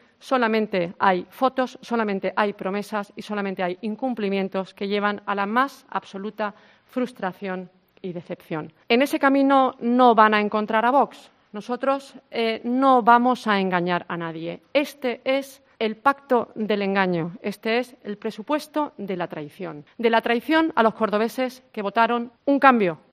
Así lo ha advertido la portavoz en una rueda de prensa, junto al edil de Vox, Rafael Saco, a la vez que ha dicho que "la abstención era bastante evidente, porque los presupuestos son absolutamente continuistas, los mismos que el del PSOE" en su mandato, y ha agregado que "es triste que se siga intentando engañar a la gente", dado que "el presupuesto que se ha planteado para 2021 no es la solución de nada", según ha subrayado.